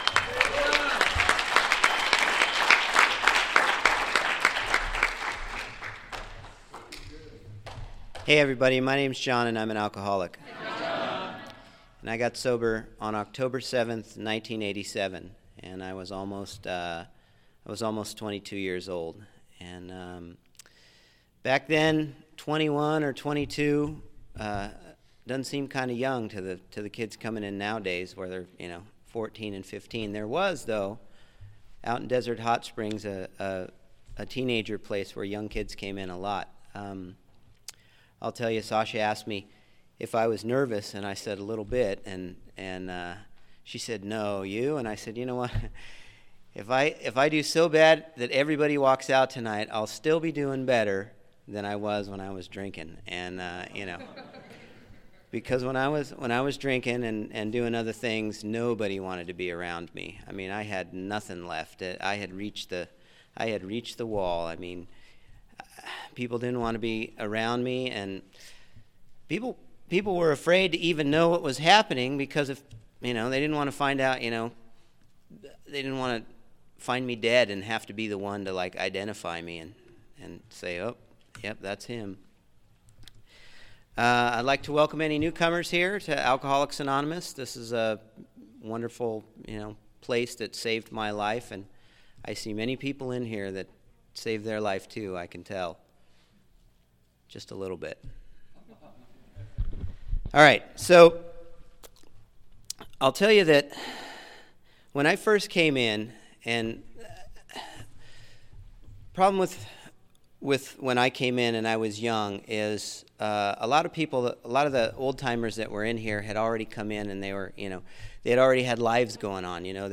Friday AA Speaker